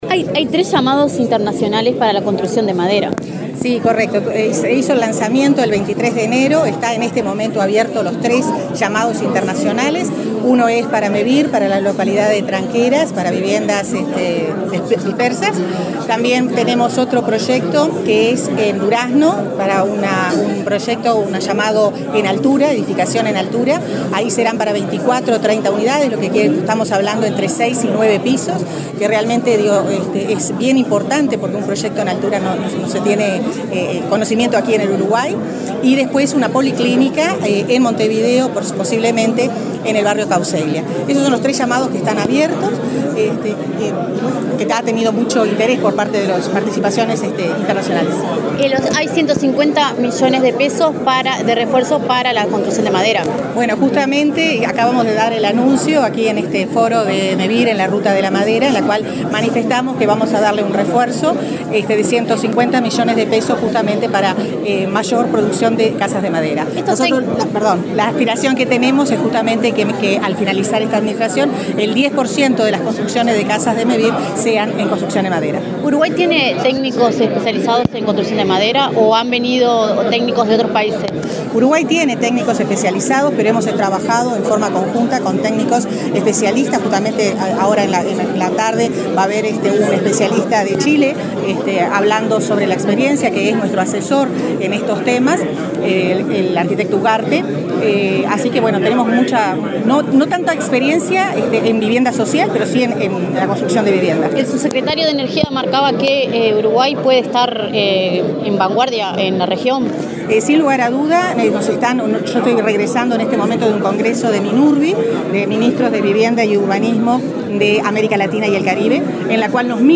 Declaraciones de la ministra de Vivienda, Irene Moreira